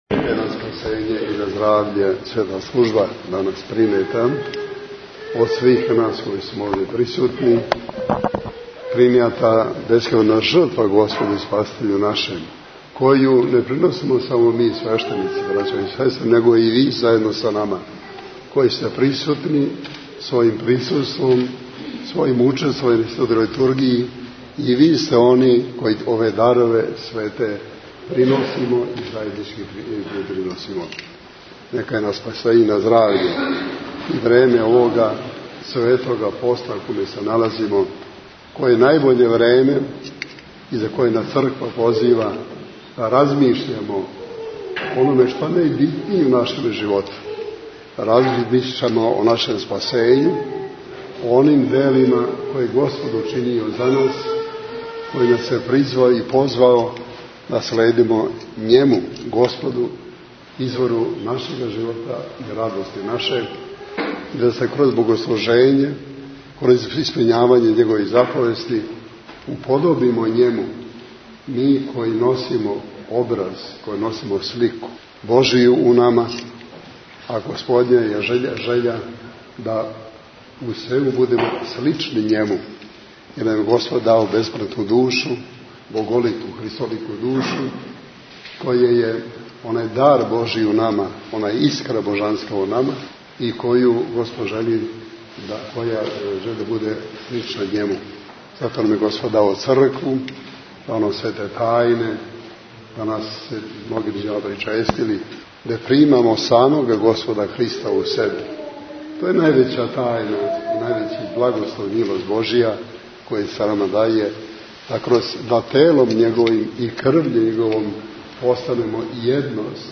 Tagged: Бесједе Наслов: Njegova Svetost Patrijarh srpski G. Irinej Албум: Besjede Година: 2011 Величина: 4:04 минута (719.46 КБ) Формат: MP3 Mono 22kHz 24Kbps (CBR) Његова Светост Патријарх Српски Господин Иринеј служио је у сриједу 6. априла Литургију пређеосвећених дарова у Храму Светог Трифуна у београдском насељу Мали Мокри Луг.